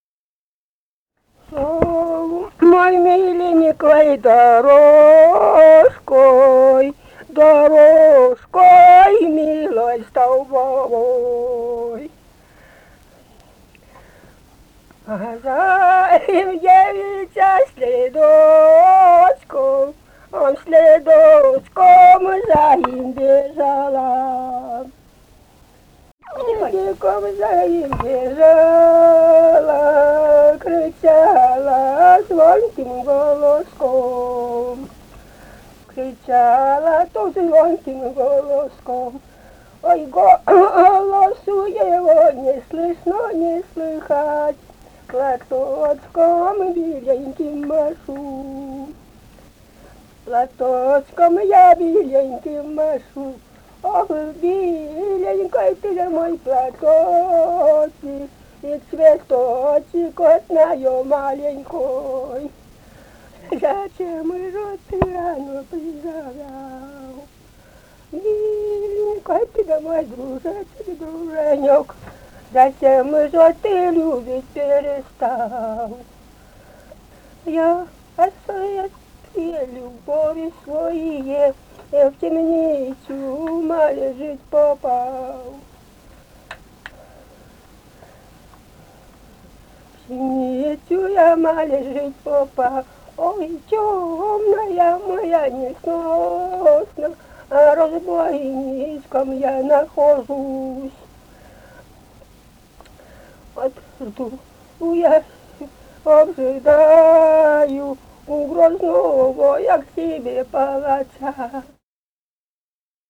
«Шёл миленькой дорожкой» (солдатская).